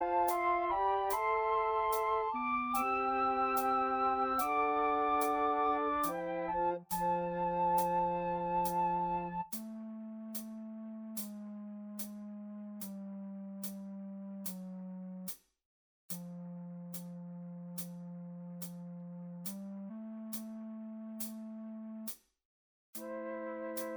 Minus All Guitars Rock 8:06 Buy £1.50